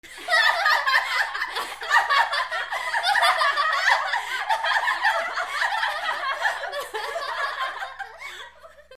/ M｜他分類 / L50 ｜ボイス / 球場ガヤ素材_2017_0806
27 女 複数 笑い声